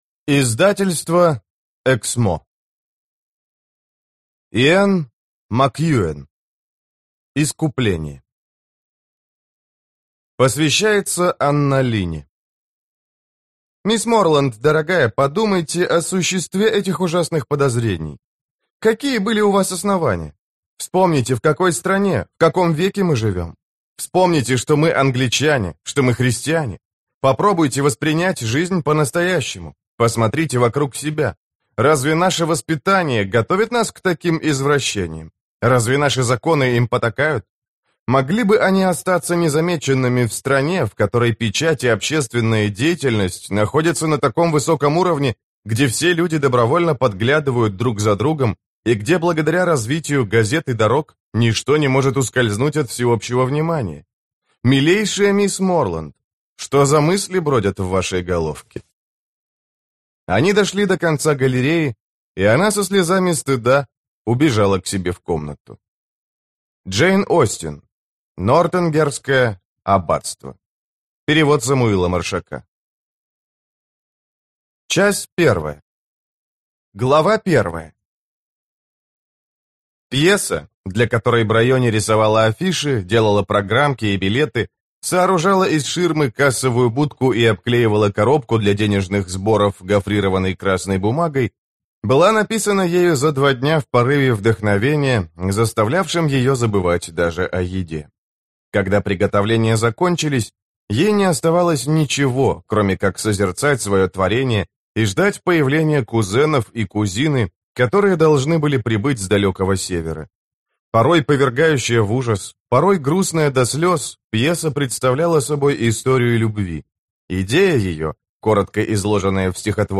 Аудиокнига Искупление - купить, скачать и слушать онлайн | КнигоПоиск